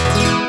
SpeechOn.wav